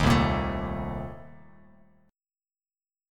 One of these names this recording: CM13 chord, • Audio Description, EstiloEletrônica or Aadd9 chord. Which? CM13 chord